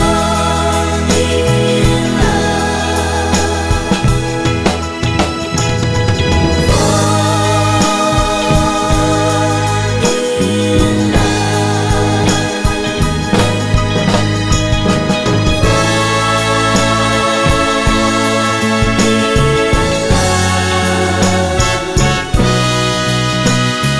Live Version Pop (1960s) 4:01 Buy £1.50